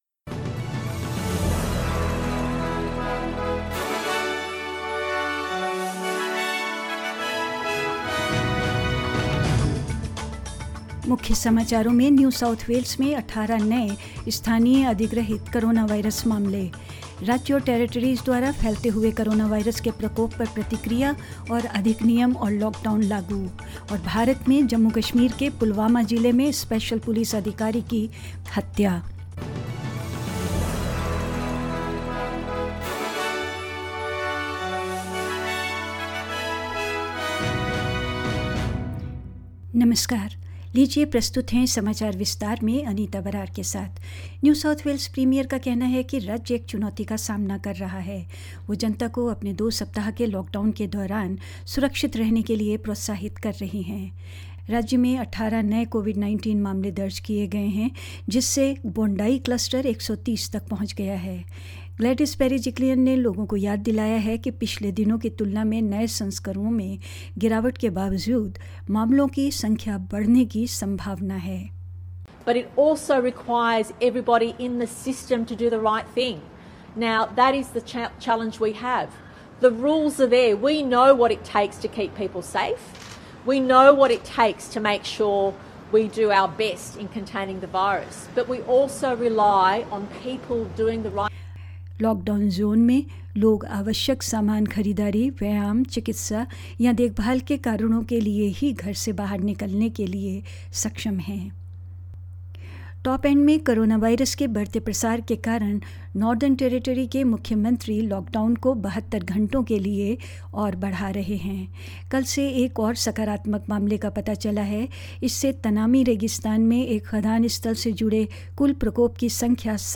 In this latest SBS Hindi News bulletin of Australia and India: New South Wales records 18 new locally-acquired coronavirus cases; States and territories react to the evolving coronavirus outbreak, implementing more rules and lockdowns; In India, Special Police Officer of Jammu and Kashmir’s Pulwama is killed in an attack, and more news.